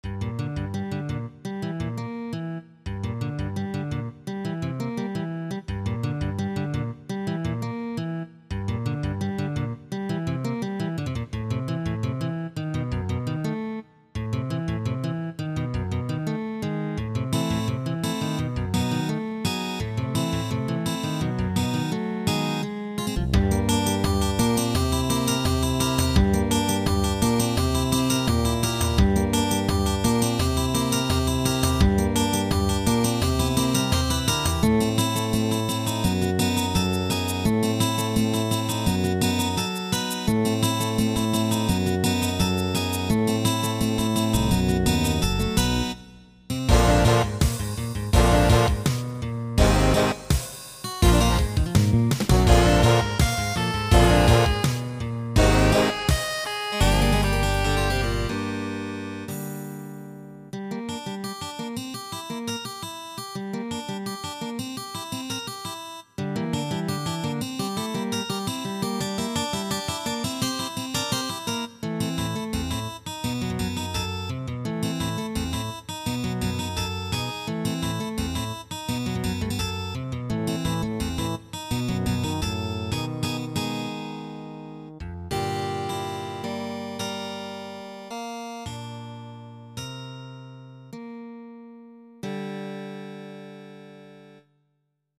Since it is not in Darrell Abbott's style, but is still guitar-oriented, I chose a new title that better matches the music.
ETHNIC MUSIC ; ROCK MUSIC